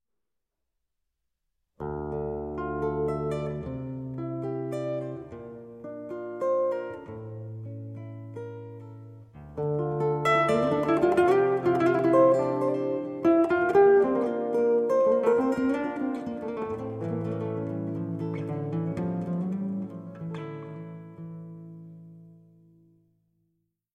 Tenor
Gitarre